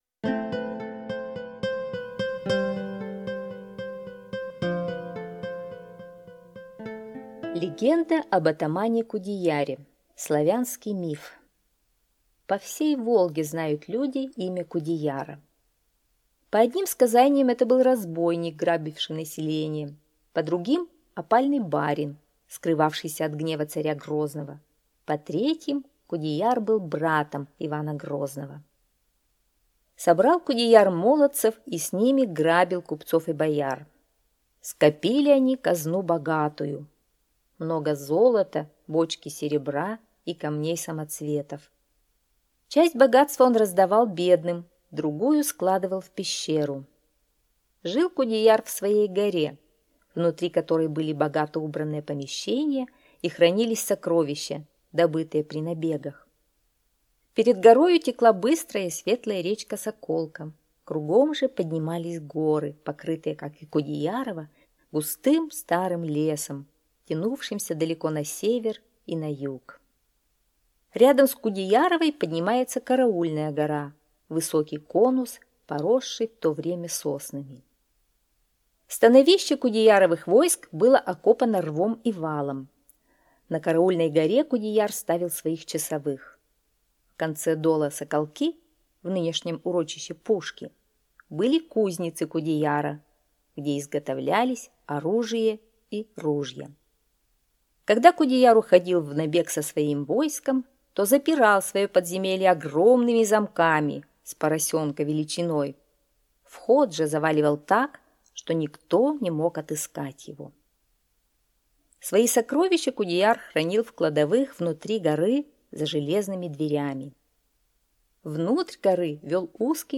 Аудиосказка «Легенда об атамане Кудеяре» – Славянский миф
Голос такой приятный а так легенда интересная